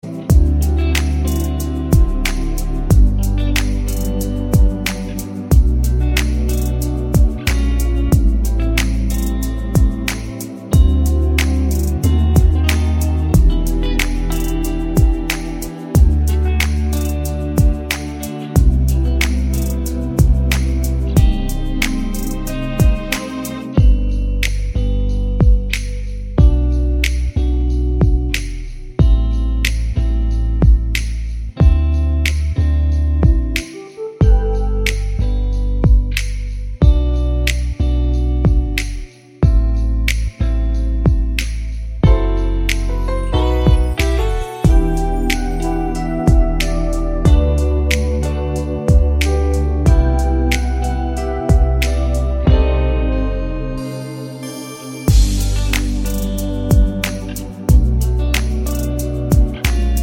no Backing Vocals Christmas 3:23 Buy £1.50